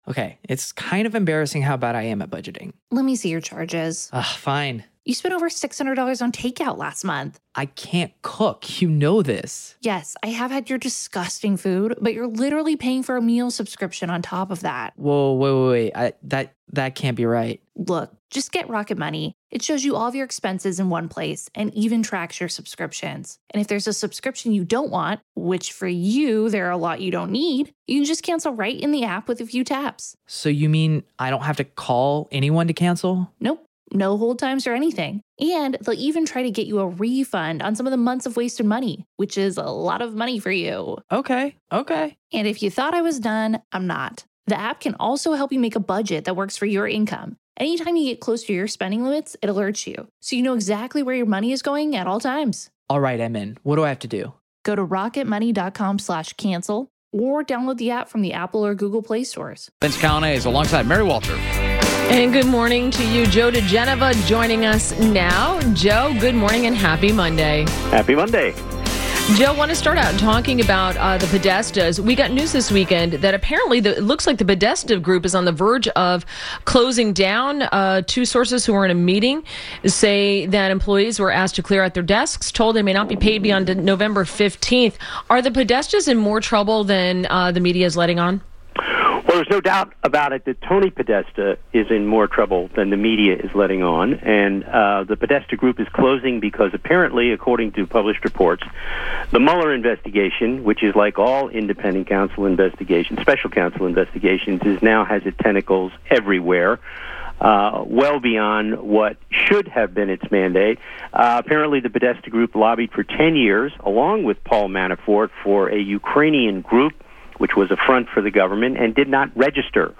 WMAL Interview - JOE DIGENOVA - 11.13.17
Interview - JOE DIGENOVA - legal analyst and former US Attorney to the District of Columbia – discussed the Podesta Group and Roy Moore.